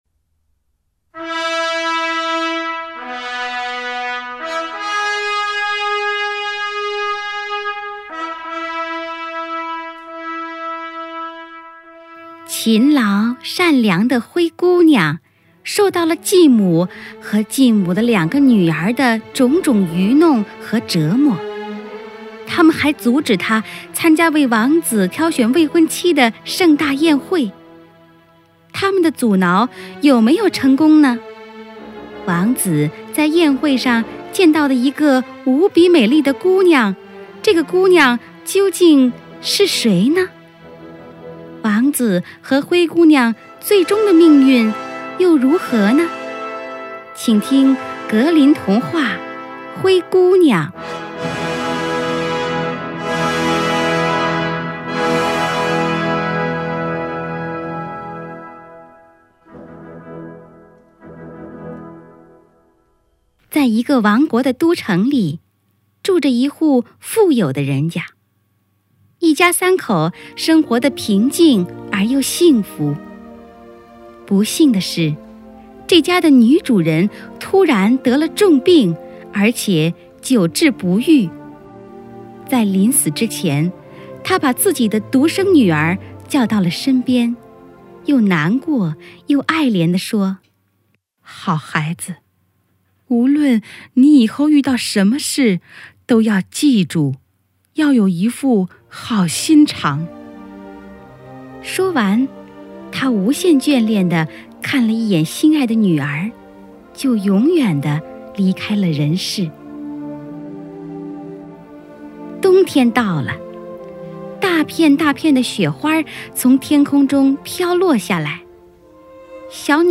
Audiobook «Cinderella» in Chinese (灰姑娘)